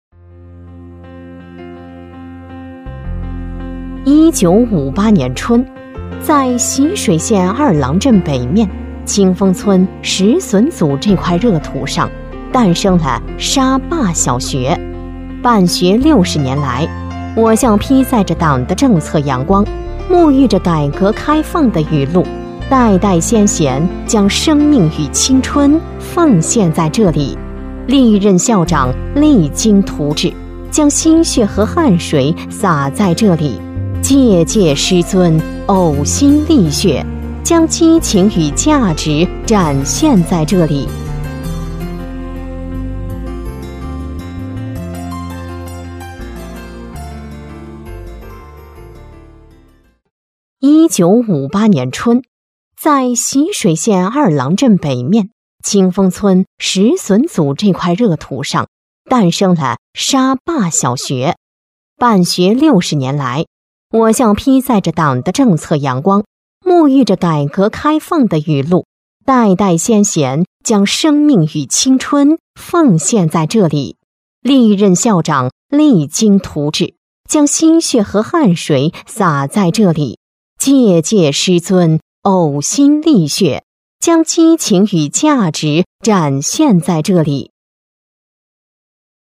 男国186_专题_政府_缙云县税务局_稳重
标签： 稳重
配音风格： 激情 稳重 浑厚